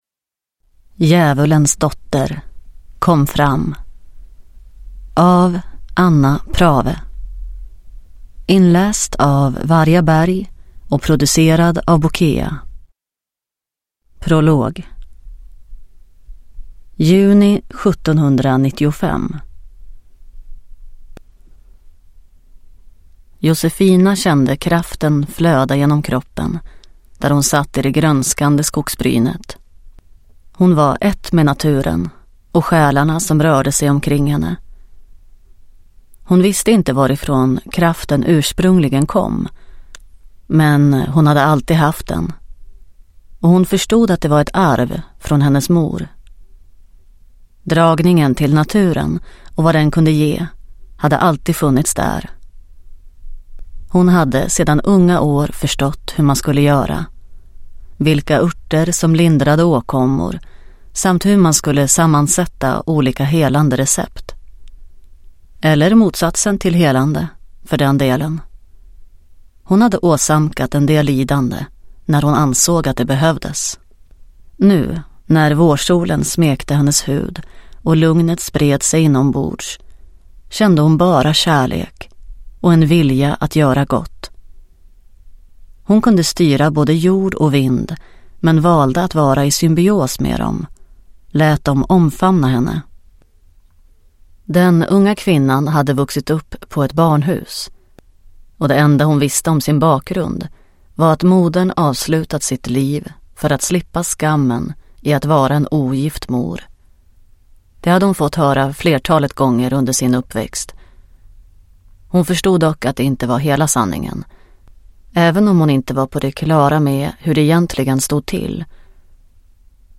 Djävulens dotter, kom fram – Ljudbok